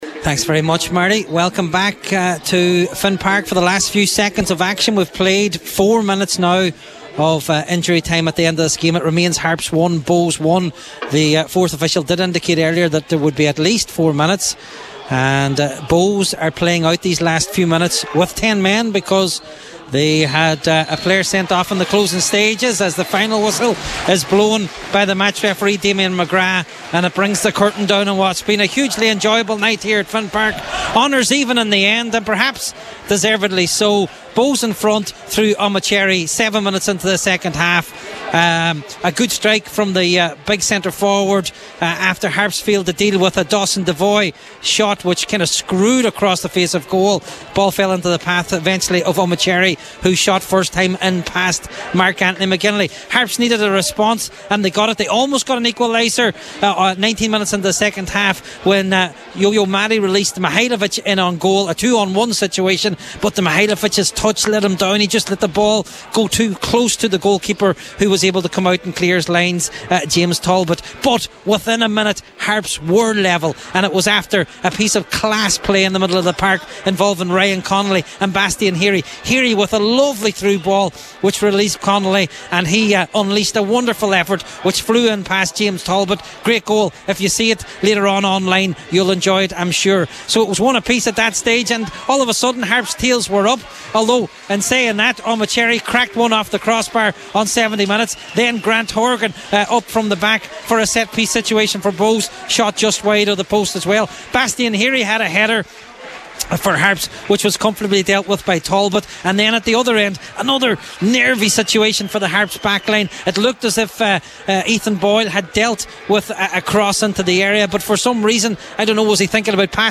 FT Report: Finn Harps 1 Bohemians 1